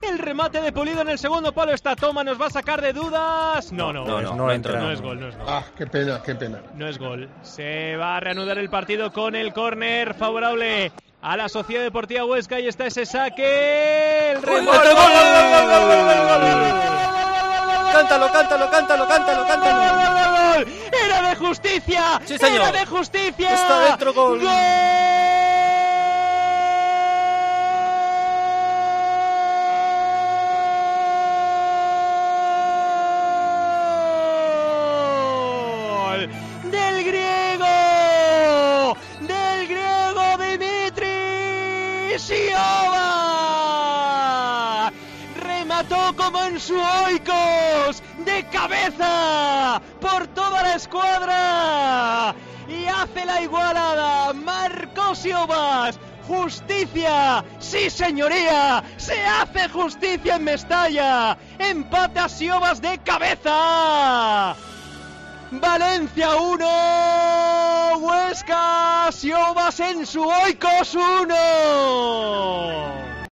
Narración Gol de Siovas / Valencia 1-1 SD Huesca